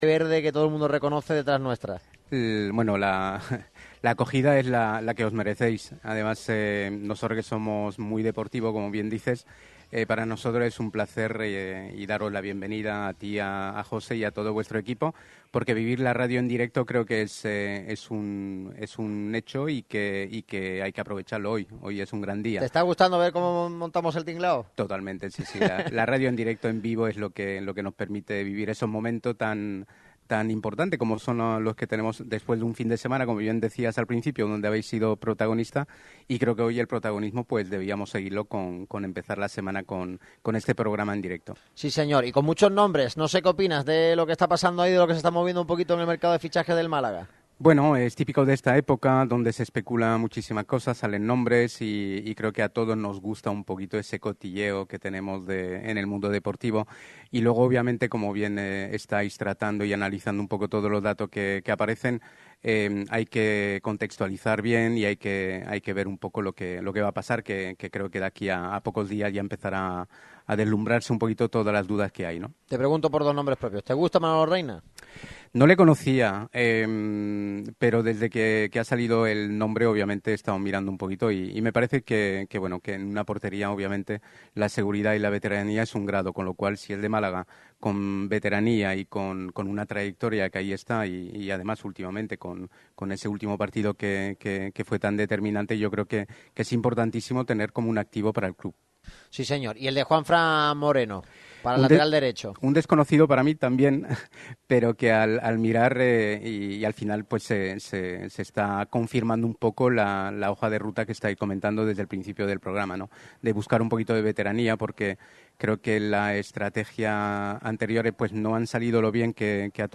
El equipo de Radio MARCA Málaga sigue moviéndose, y esta misma mañana ha sido acogido, de la mano de Grupo PROTEC, en uno de los establecimientos de Pinturas Andalucía.